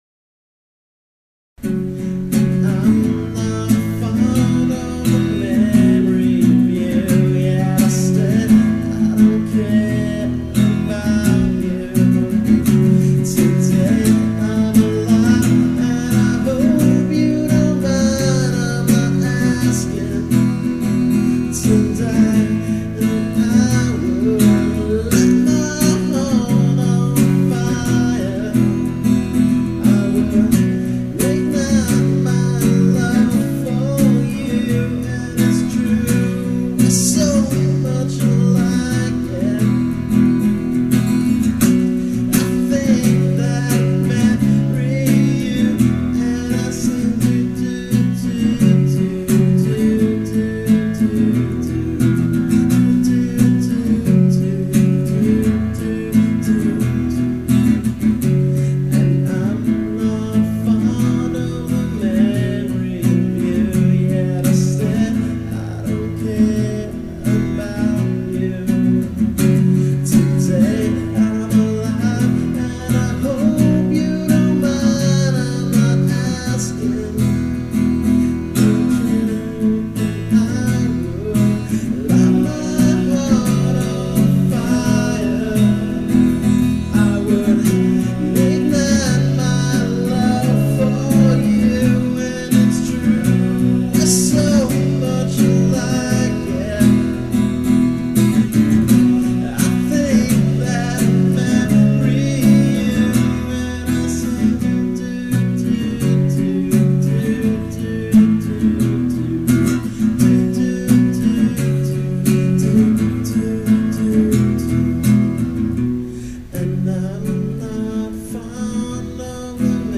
solo acoustic act